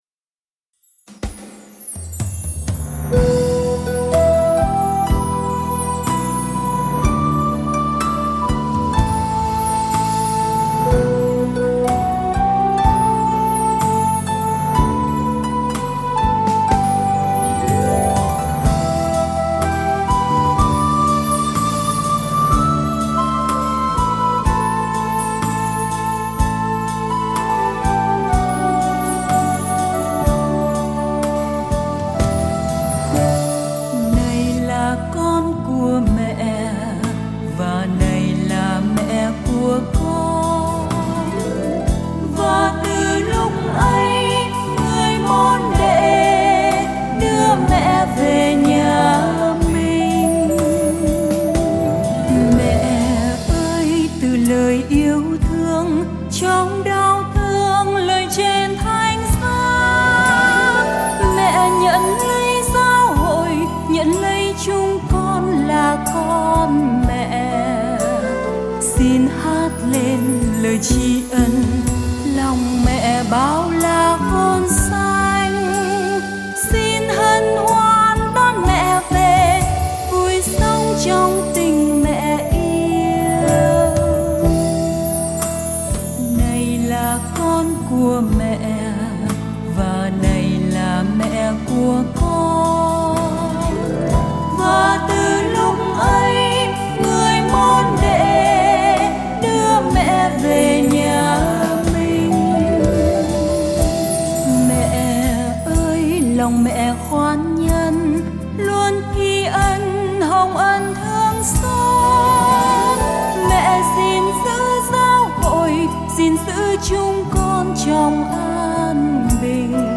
Thánh ca về Đức Mẹ